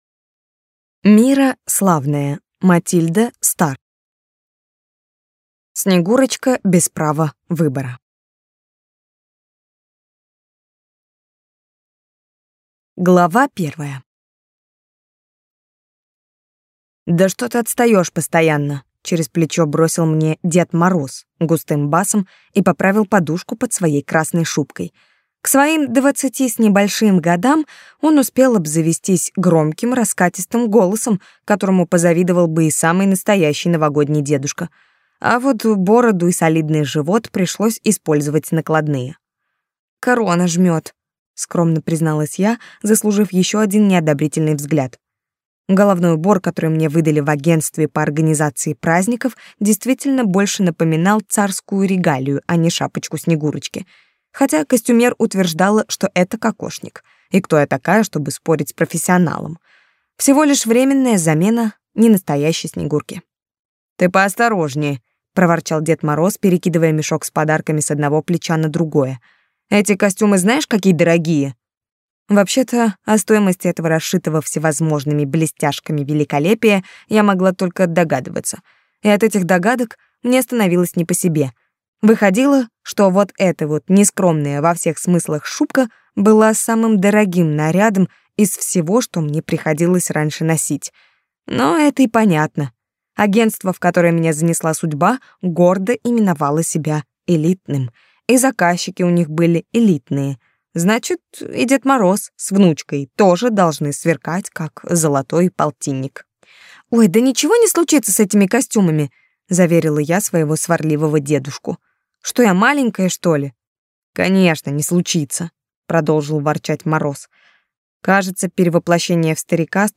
Аудиокнига Снегурочка без права выбора | Библиотека аудиокниг
Прослушать и бесплатно скачать фрагмент аудиокниги